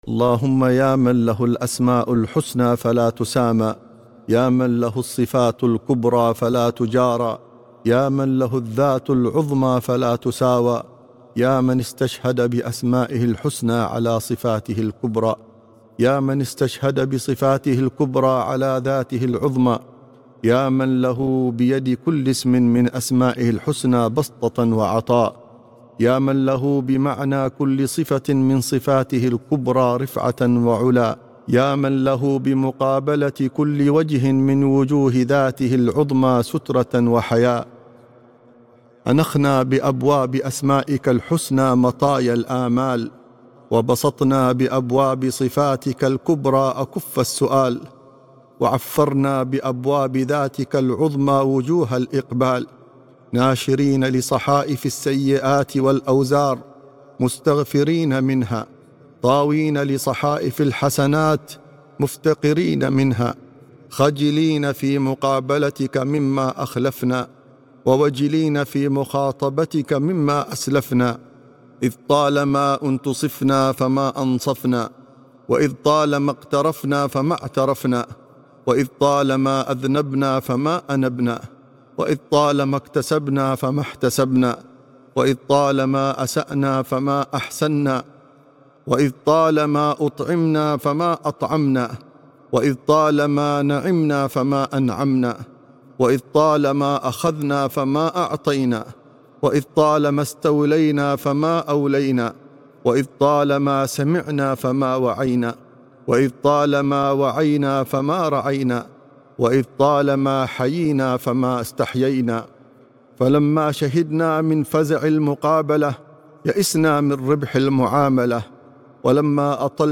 دعاء الأسماء والصفات